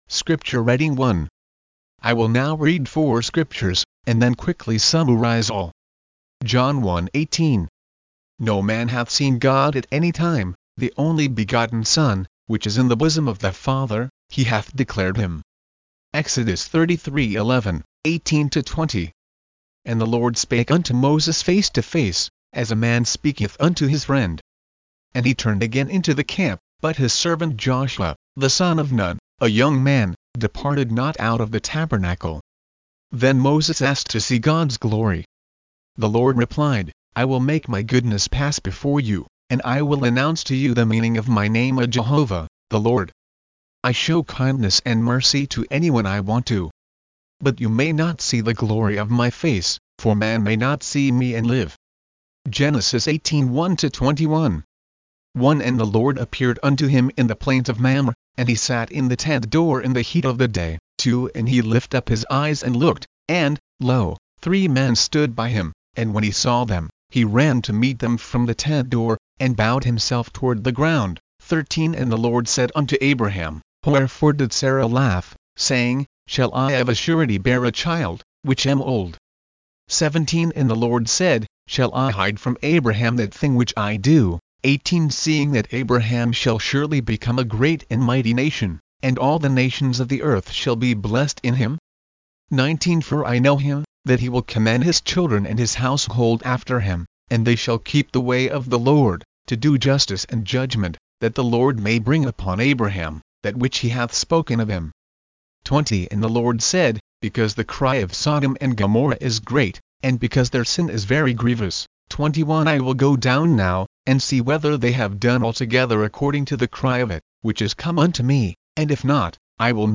Scriture Reading
Scripture_Reading01.mp3